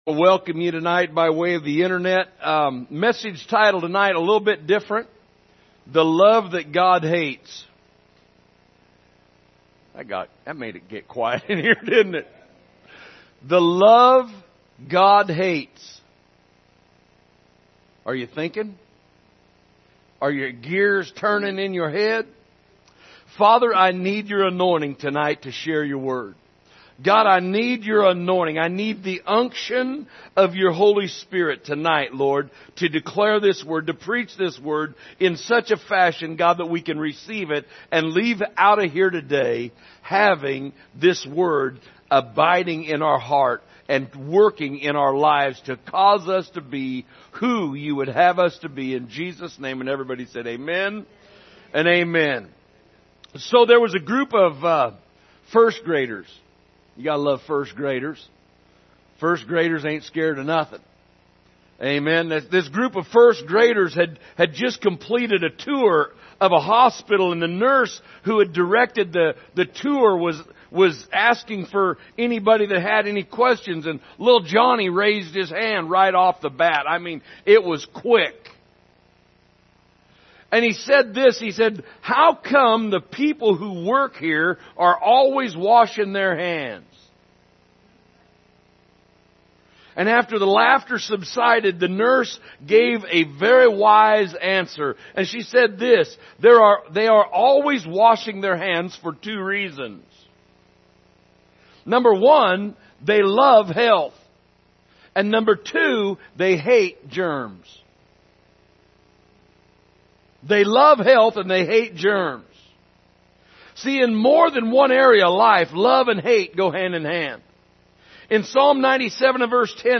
Sunday Evening Service October 9, 2022 – The Love God Hates
Category: Sermons